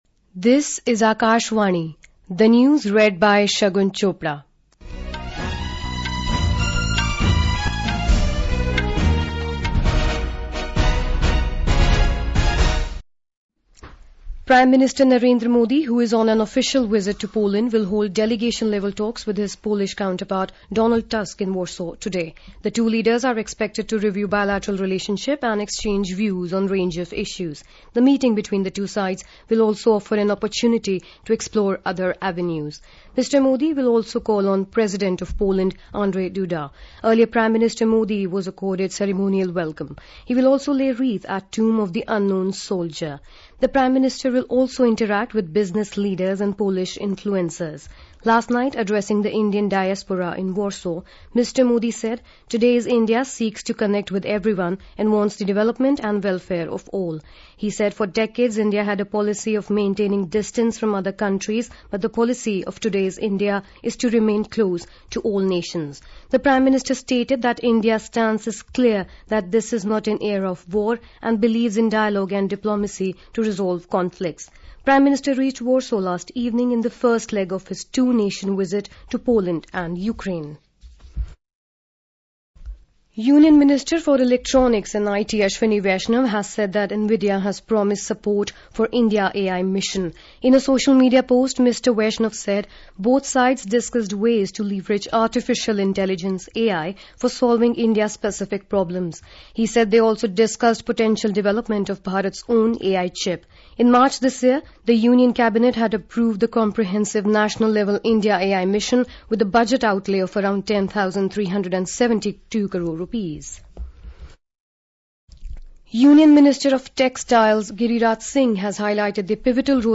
National Bulletins
Hourly News